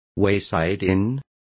Also find out how paradores is pronounced correctly.